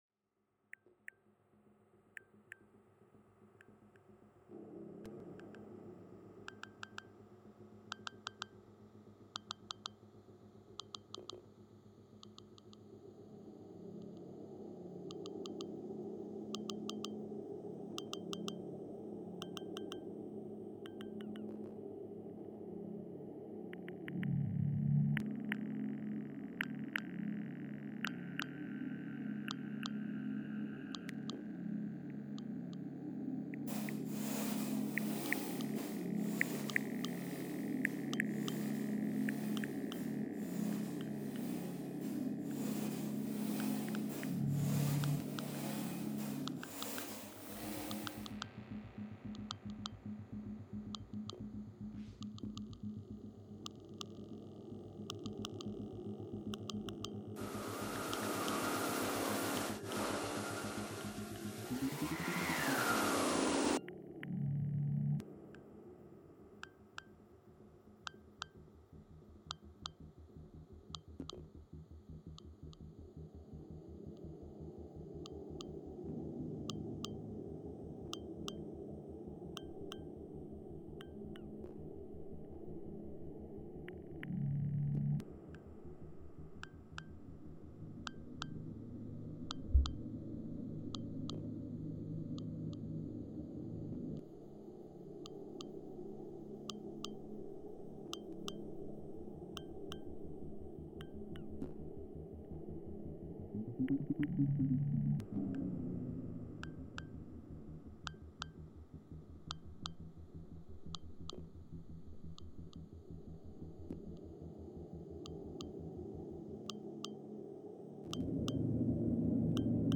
sine_sine-1.mp3